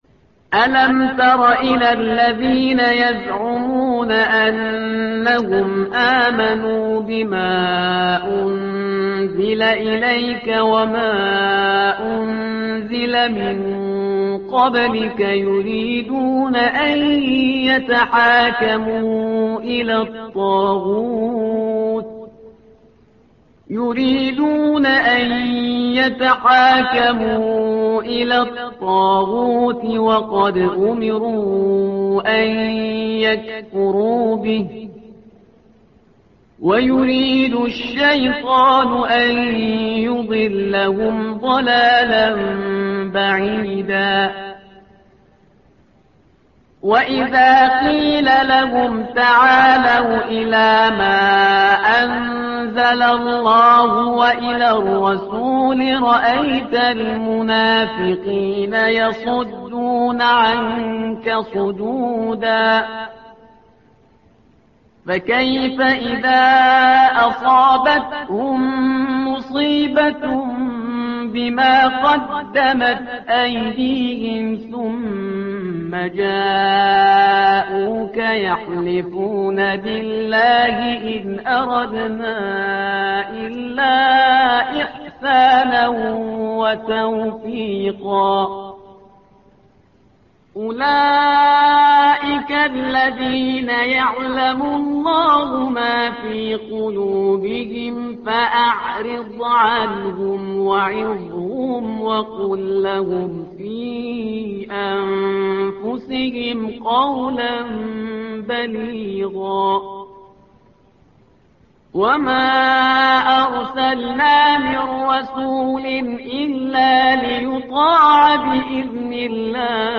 الصفحة رقم 88 / القارئ